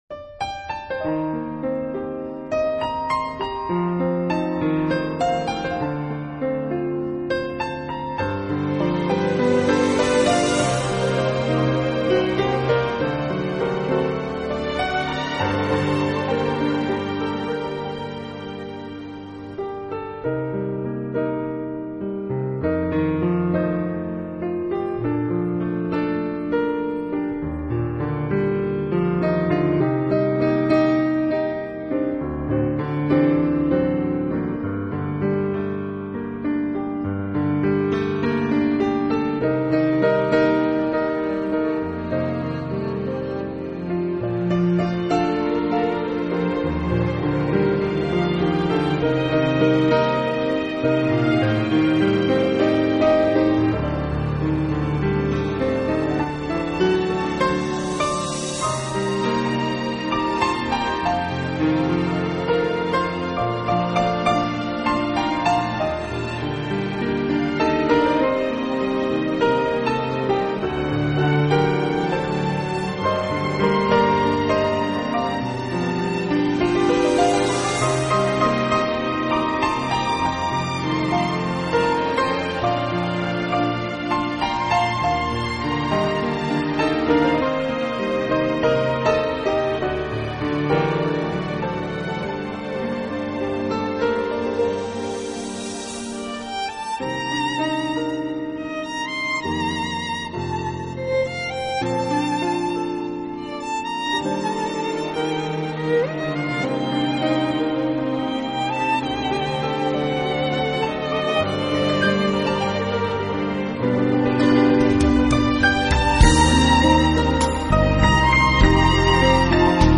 【钢琴】
典雅的古钢琴演奏的风格迥异之作品。